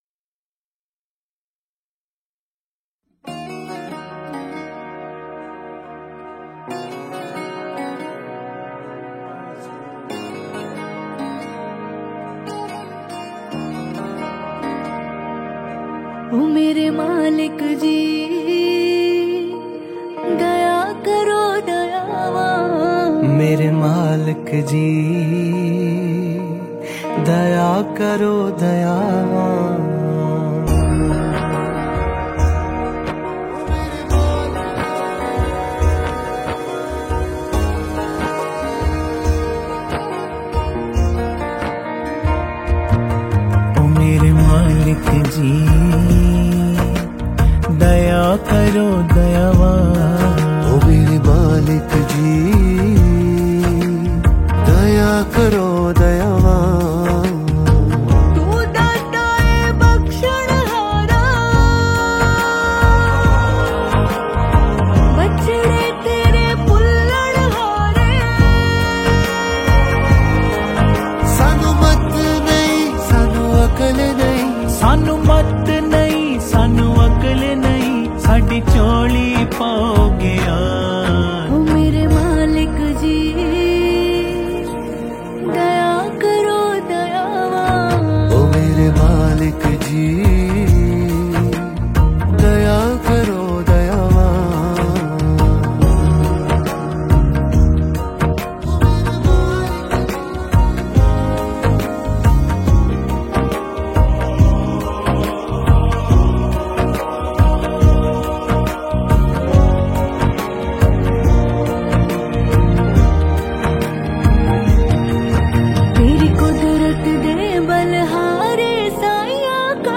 Category New Punjabi Song 2023 Singer(s